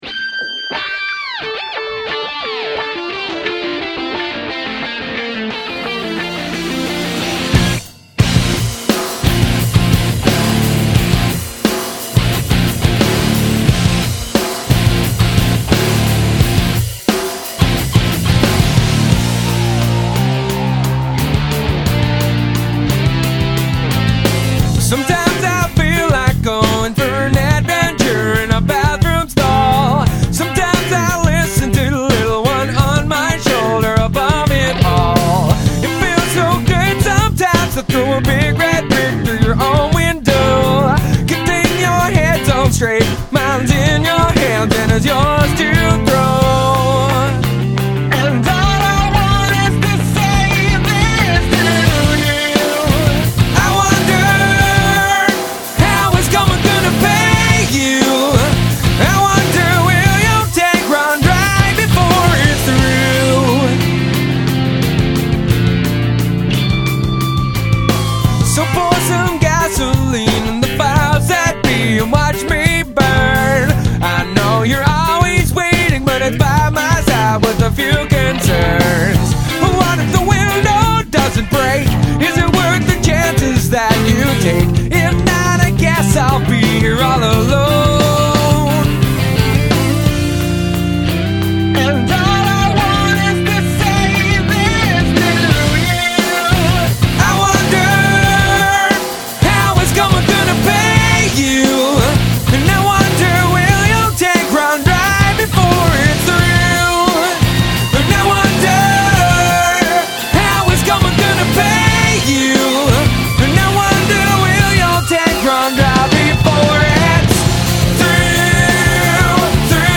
Rock & Roll
Prog rock